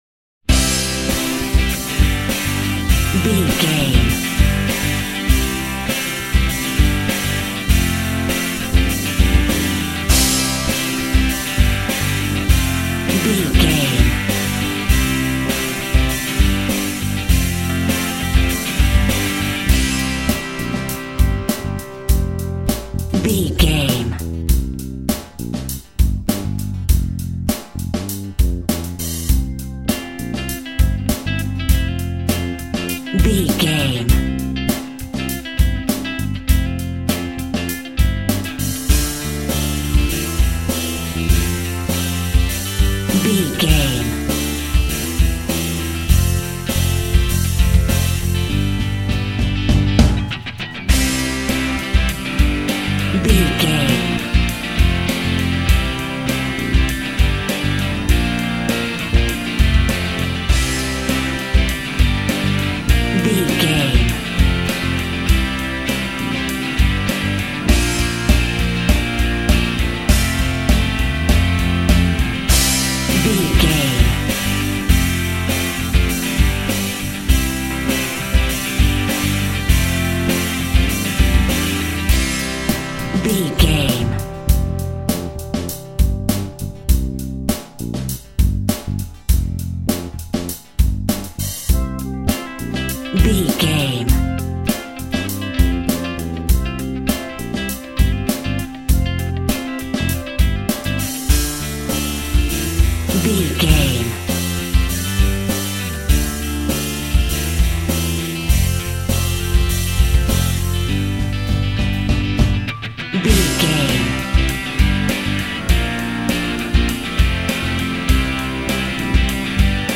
Epic / Action
Fast paced
Ionian/Major
cheesy
Power pop
drums
bass guitar
electric guitar
piano
hammond organ